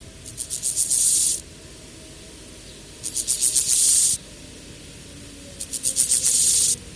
������������ ������� ����������� Chorthippus dorsatus.